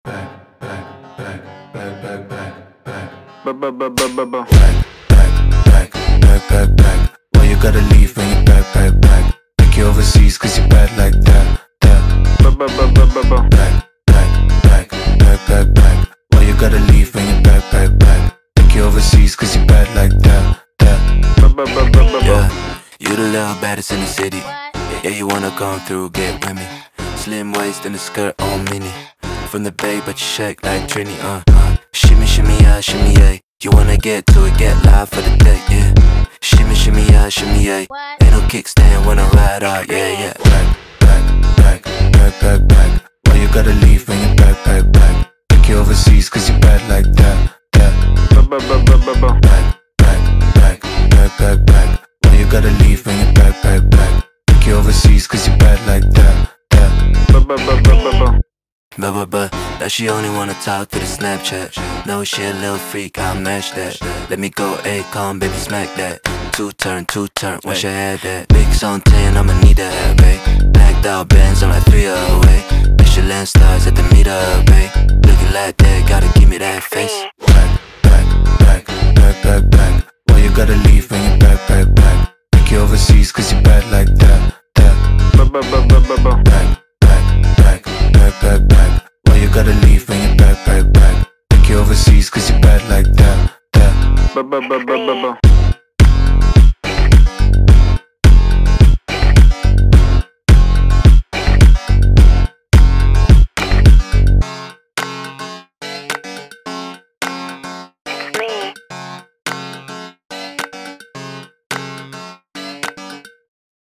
Трек размещён в разделе Зарубежная музыка / Рэп и хип-хоп.